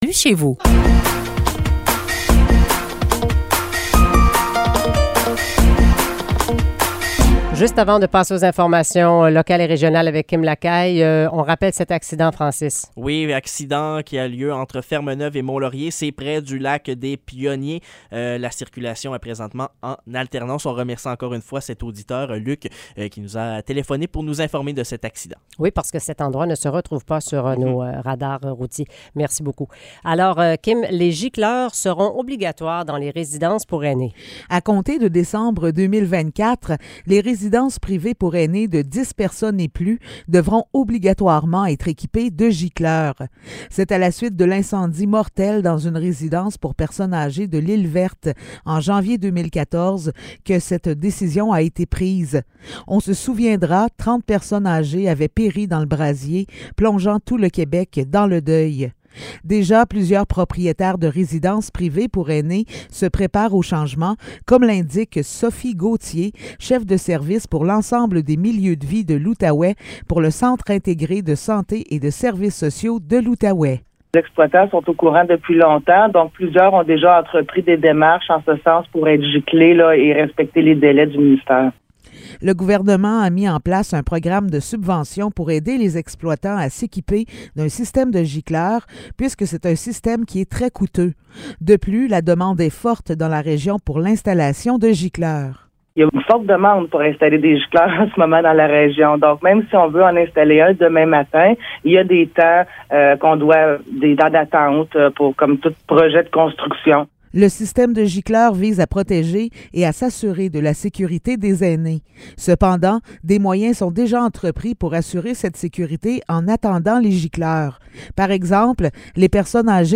Nouvelles locales - 16 février 2023 - 8 h